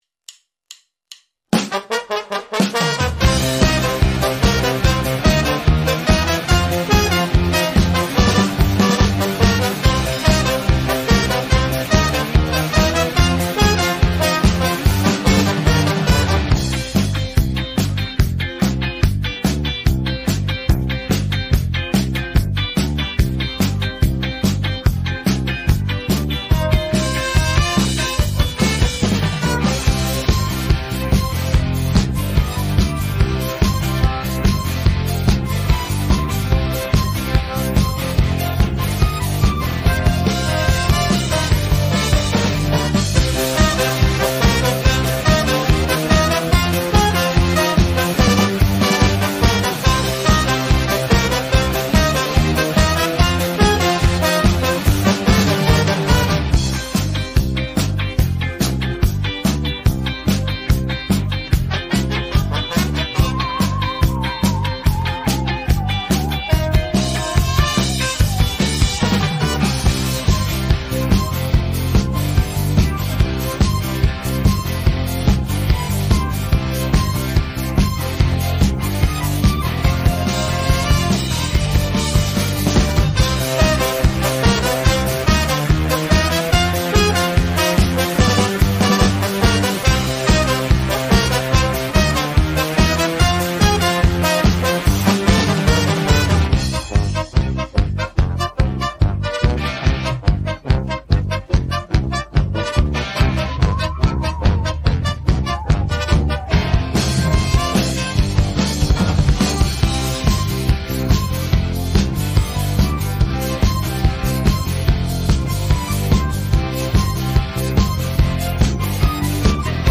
rock караоке